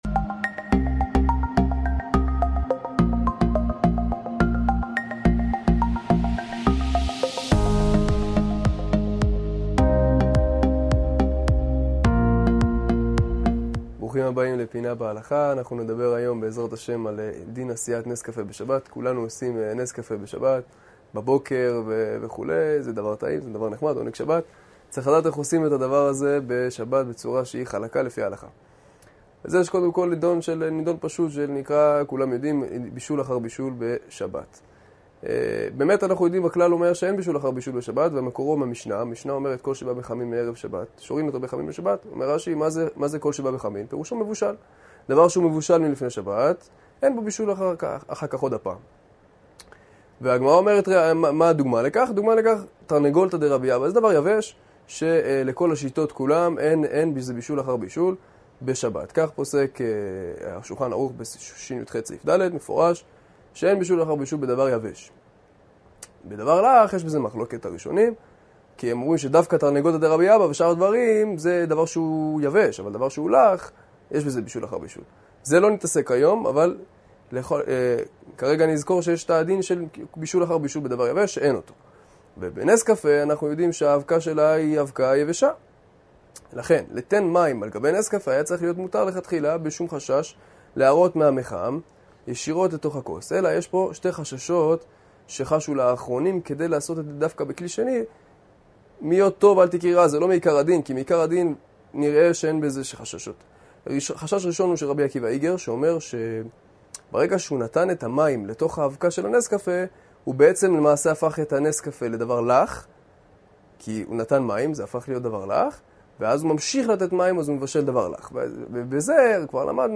מעביר השיעור: אברכי ישיבת הכותל